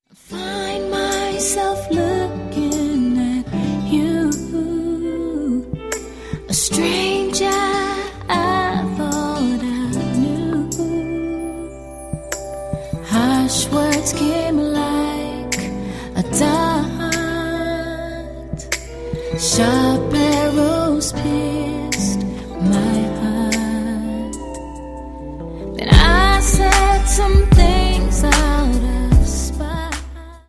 • Sachgebiet: Gospel